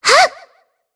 Shea-Vox_Attack1_jp.wav